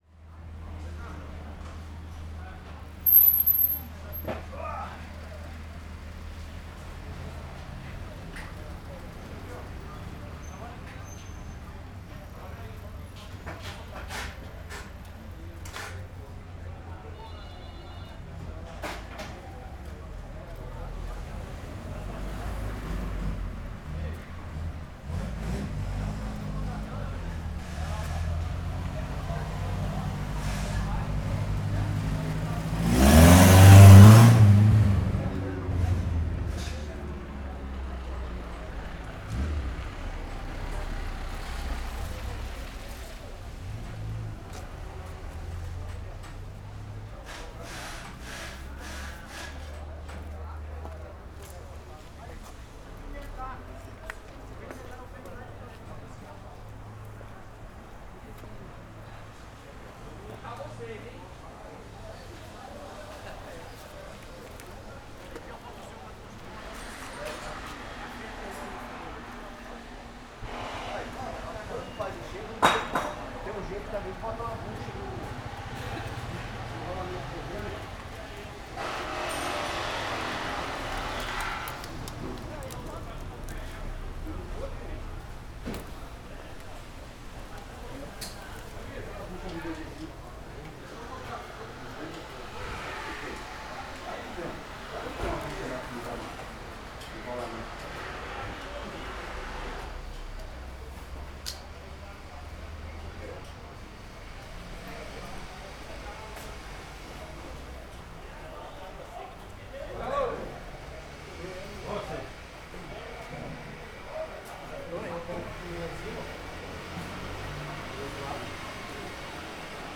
Arquivo de Homem grita - Coleção Sonora do Cerrado
CSC-04-169-OL- Oficina homem grita marteladase parafusadeira baixas.wav